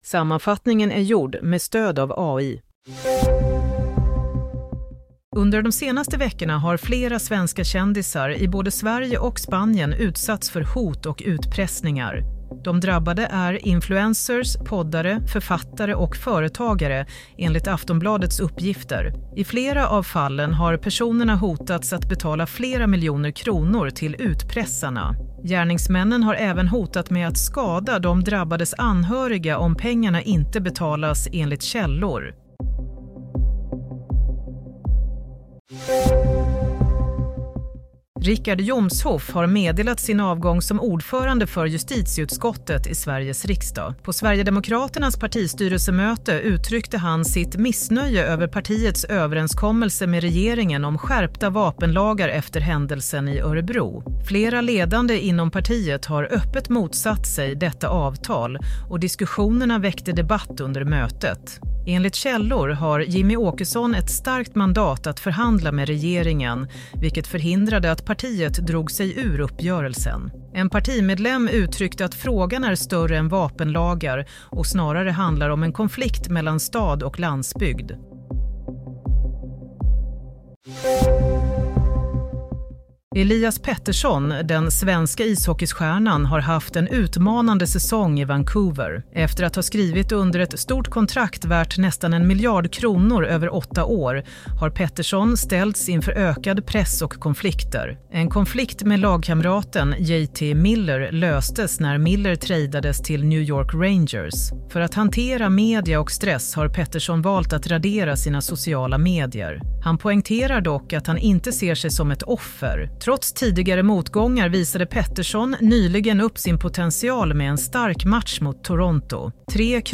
Nyhetssammanfattning - 11 februari 07.30